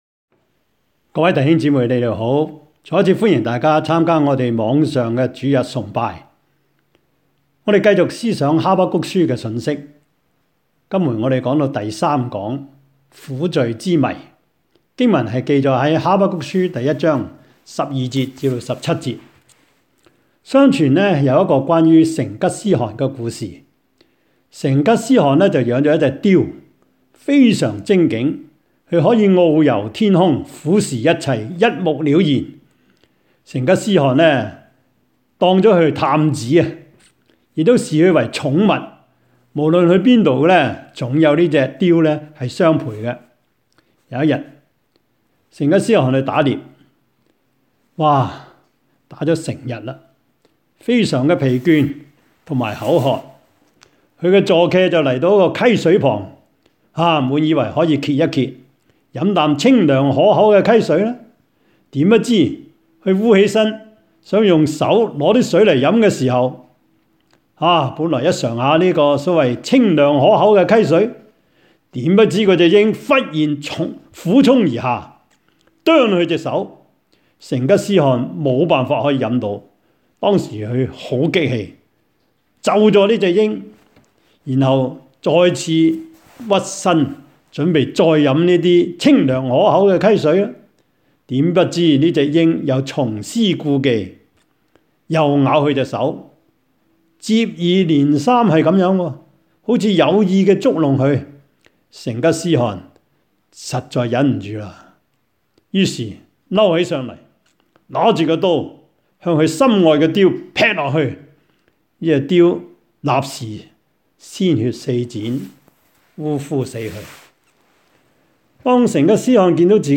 三藩市播道會主日網上崇拜
Habakkuk-Sermon-3.mp3